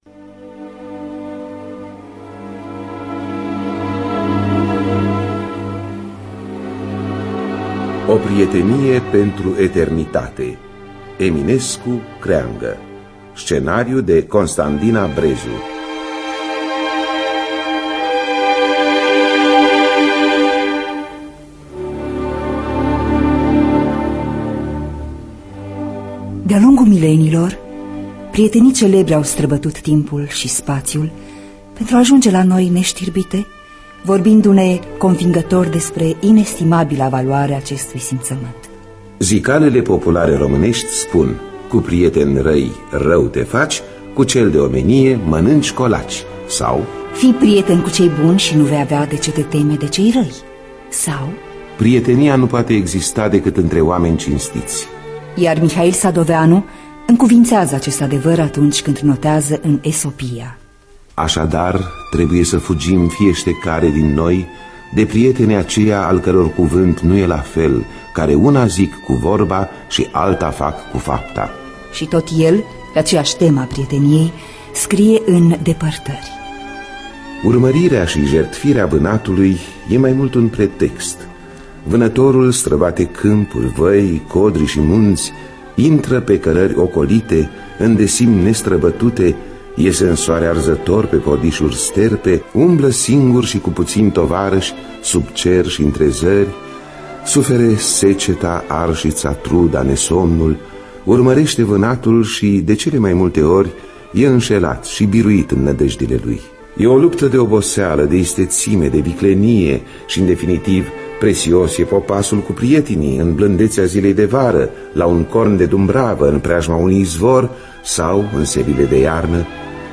Biografii, Memorii: Eminescu-Creanga – O Prietenie Pentru Eternitate (1989) – Teatru Radiofonic Online